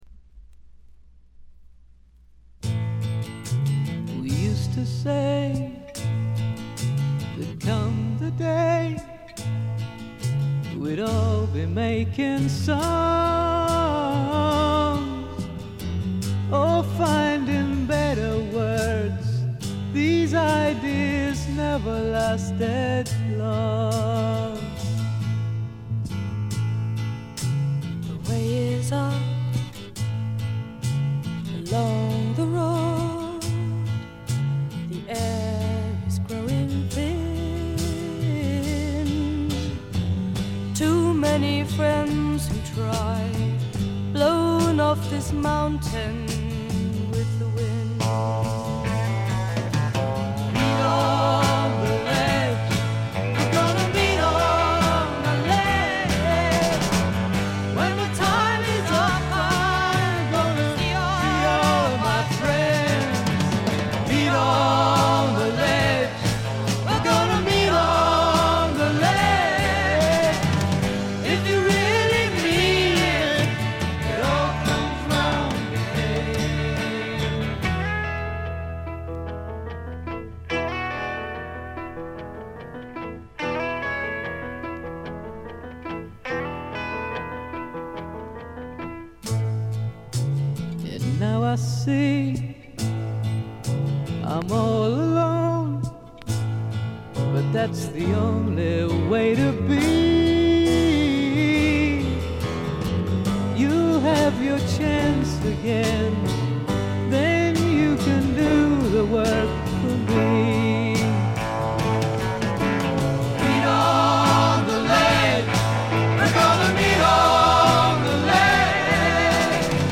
極めて良好に鑑賞できます。
英国フォークロック基本中の基本。
試聴曲は現品からの取り込み音源です。